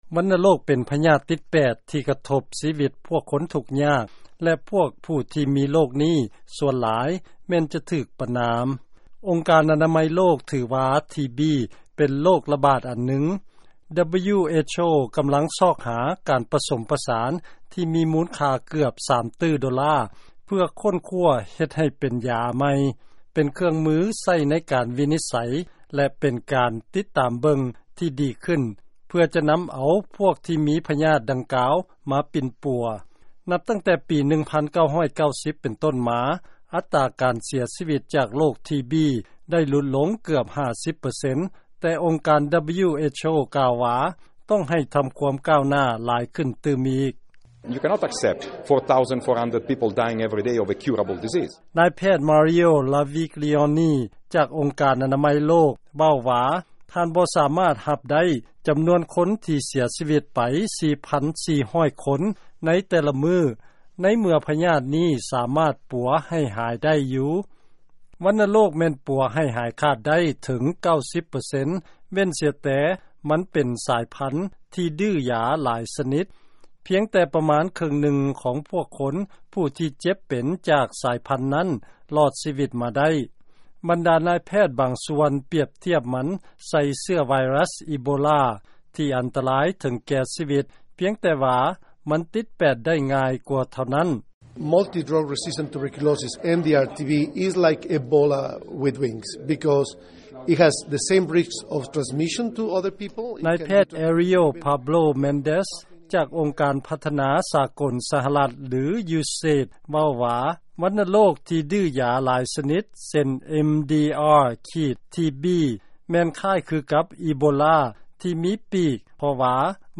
ຟັງລາຍງານ ວັນນະໂຣກ ເອົາຊີວິດ ພວກຄົນ ຫຼາຍກວ່າ ໂຣກ HIV/AIDS.